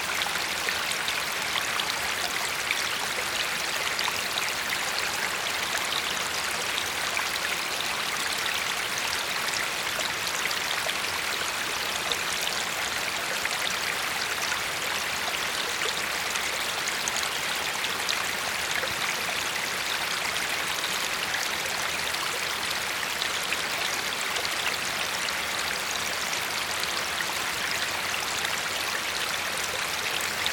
4 -- Une rivière